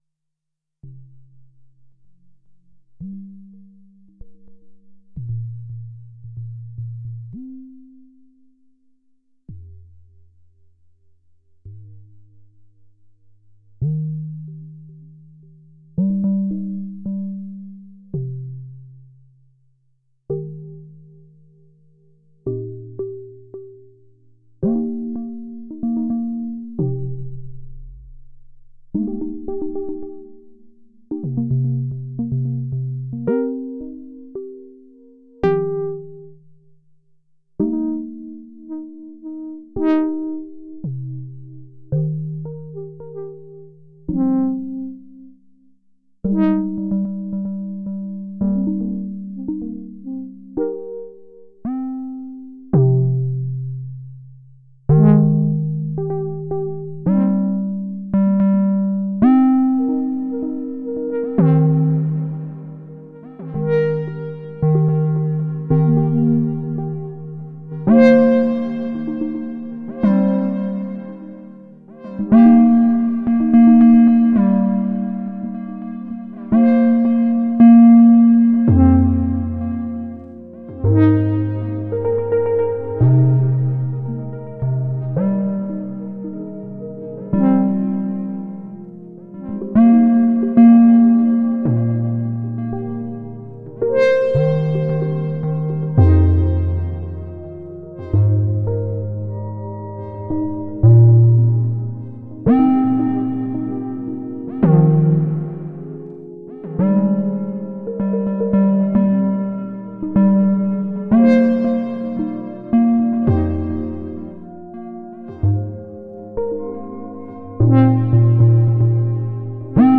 First real sit down with This wonderful synth.
Perfourmers jam partners were OP-1 Field, Monomachine, Octatrack and ZOIA EUBU sequenced by OP-Z audio going into TX-6.
As for the Perfourmer, it just sounds incredible. just turning down the filter and turning up Filter EG a tad makes me all warm and fuzzy inside. the EG is so snappy and nice, nothing gets me going like a snappy EG. linking the LFO´s is a lot of fun as well.